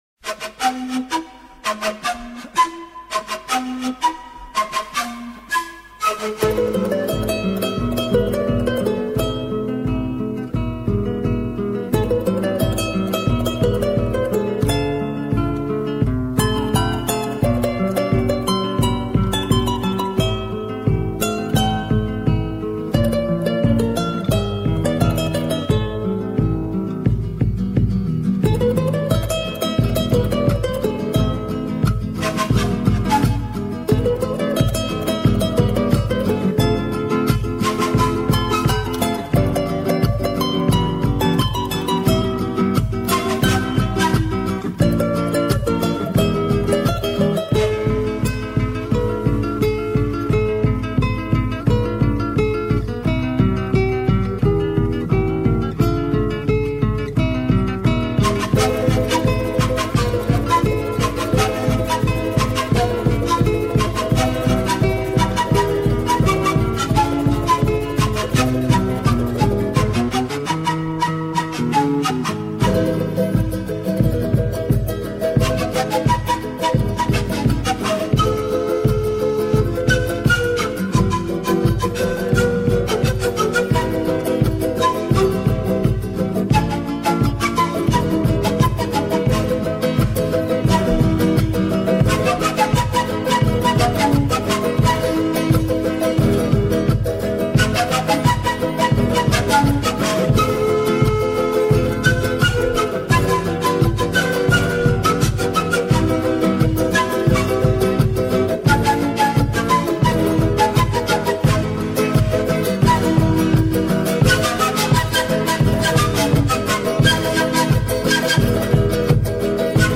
Bonito tema instrumental de los años 70.